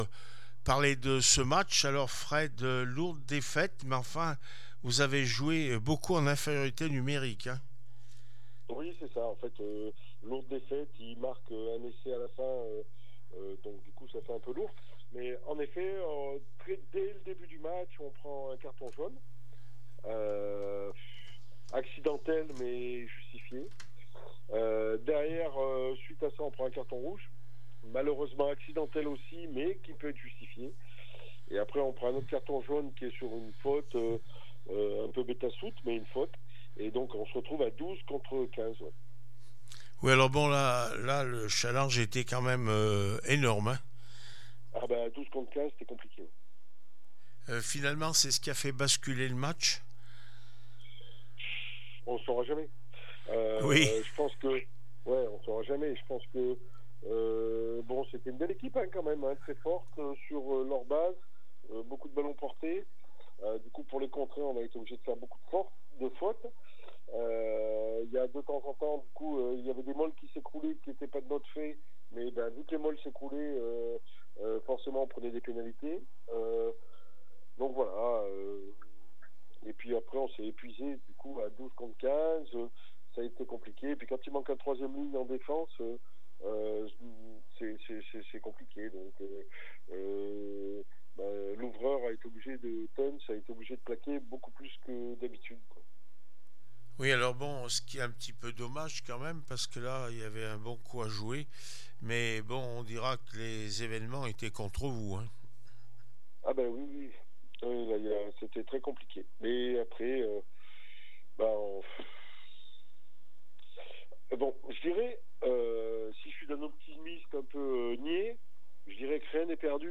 10 mars 2025   1 - Sport, 1 - Vos interviews